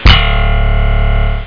1 channel
guitarb.mp3